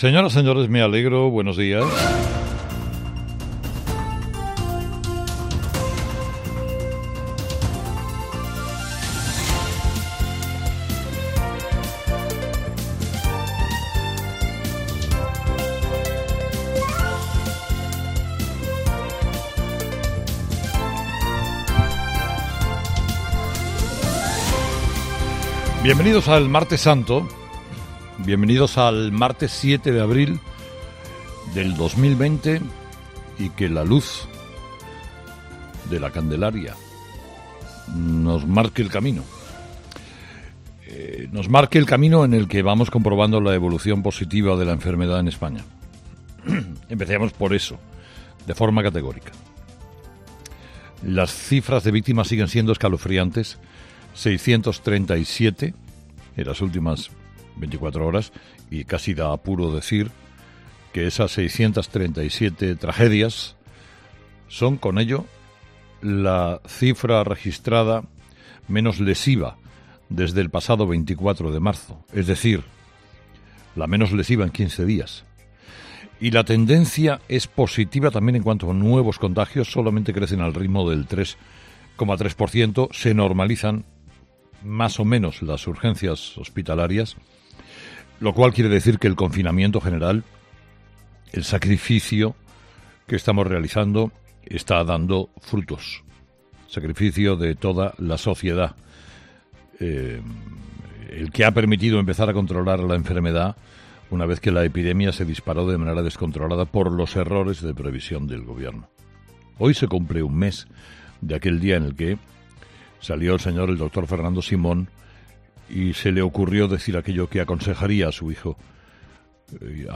ESCUCHA EL EDITORIAL DE CARLOS HERRERA